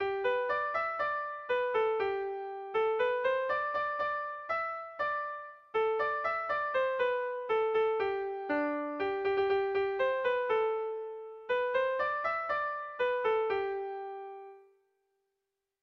Erromantzea
Kopla handiaren moldekoa
ABD